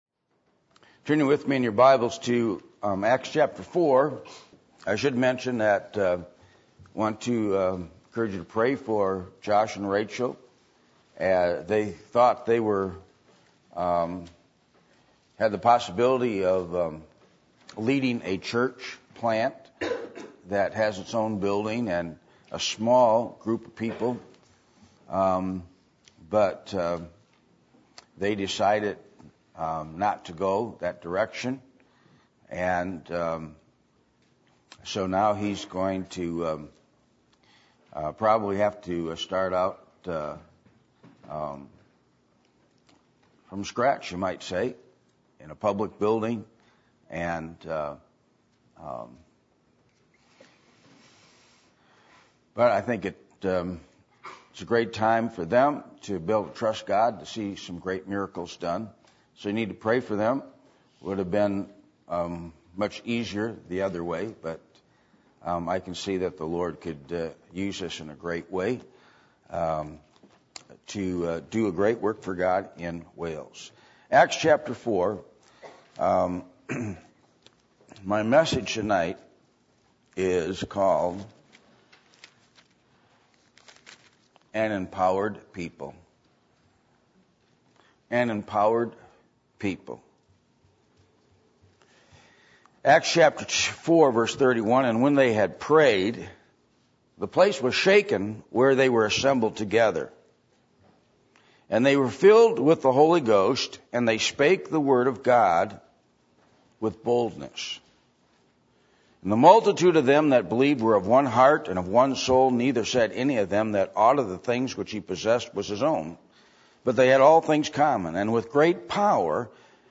Acts 4:31-35 Service Type: Sunday Evening %todo_render% « The Beauty Of The Lord Jesus A Study Of Deuteronomy 6